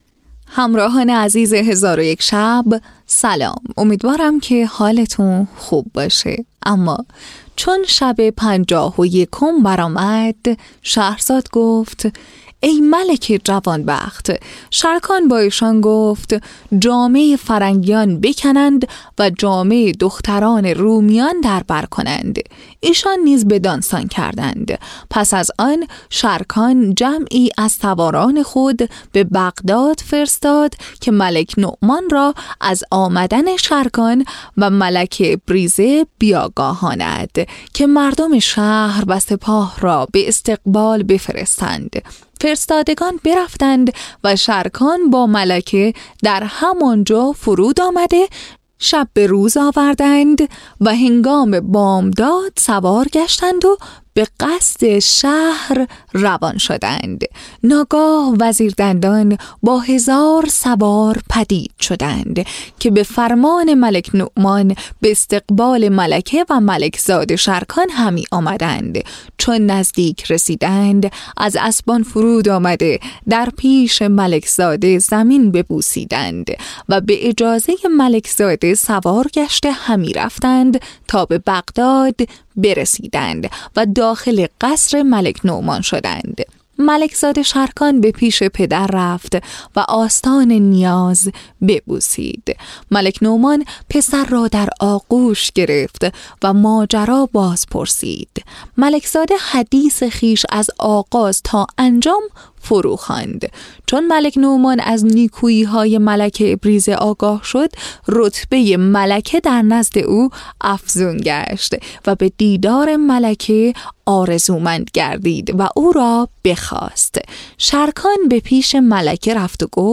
شهرزاد در شب پنجاه و یکم، با همان صدای دلنشین و روایت گری بی بدیل خود، دومین بخش از ادامه سرگذشت ملک نعمان و فرزندانش را نقل می‌کند.
تهیه شده در استودیو نت به نت